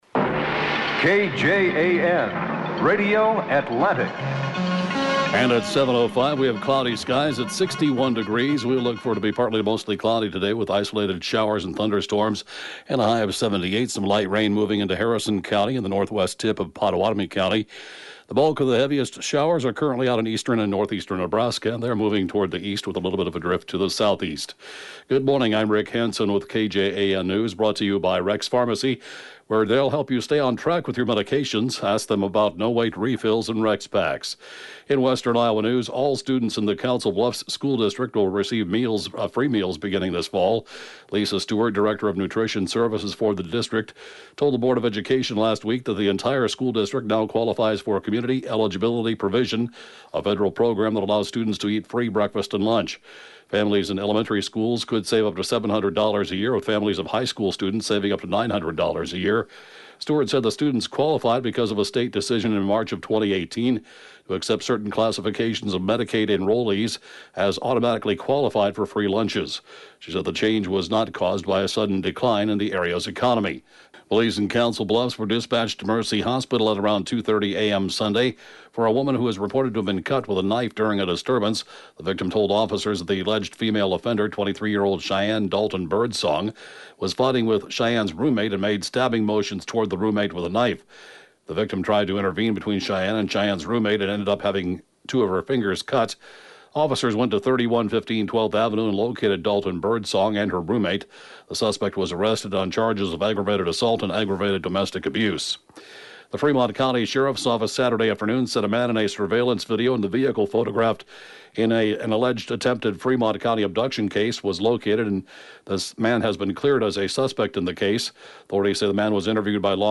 (Podcast) KJAN Morning News & Funeral report, 6/3/2019